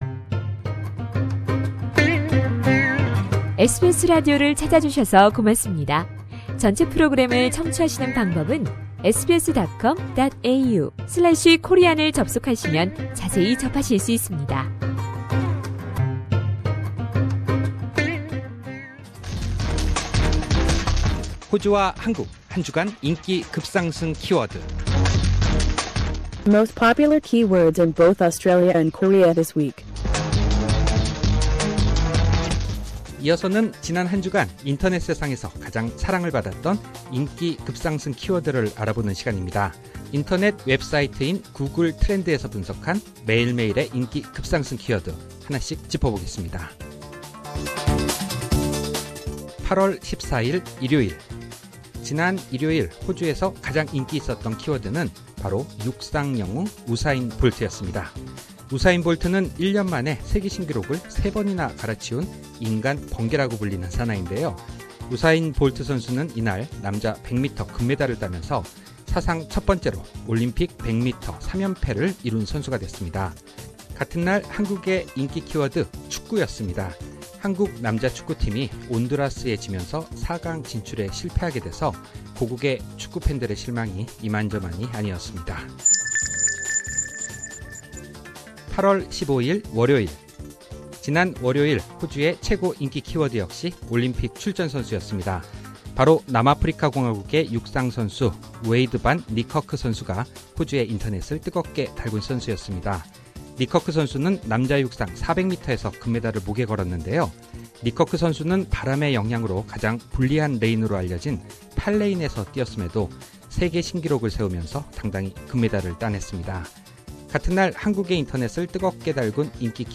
한국 1위: 유인나 (전날 첫 방송됐던 내귀에 캔디에서 한류스타 장근석의 마음을 뒤흔든 휴대전화 너머의 여성이 유인나인 것으로 밝혀지면서 유인나 열풍이 불었습니다) 상단의 다시 듣기 (Podcast)를 클릭하시면 라디오 방송을 다시 들으실 수 있습니다.